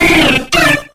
Cries
SPEAROW.ogg